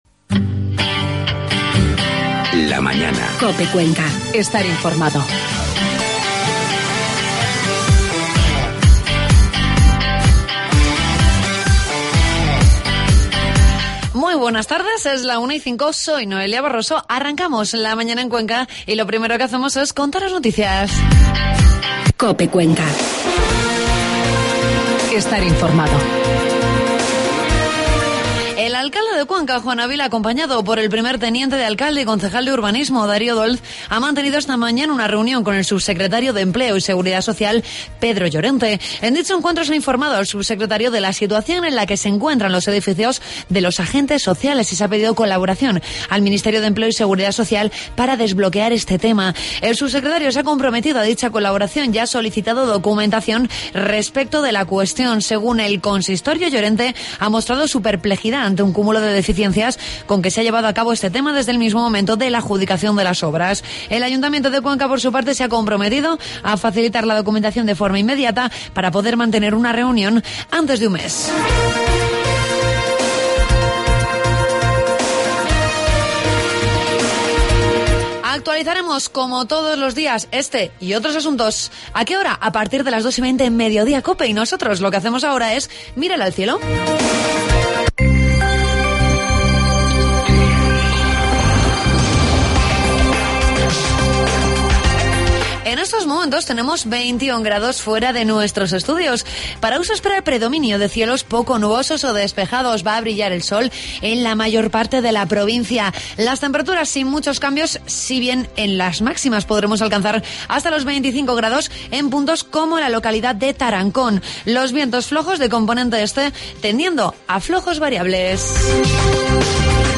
Hoy conocemos un poco más sobre el Ateneo de Cuenca que se inaugurará el próximo viernes 18 de octubre en la capital conquense charlando con varios miembros de su junta directiva